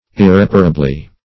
Search Result for " irreparably" : Wordnet 3.0 ADVERB (1) 1. in an irreparable manner or to an irreparable degree ; The Collaborative International Dictionary of English v.0.48: Irreparably \Ir*rep"a*ra*bly\, adv.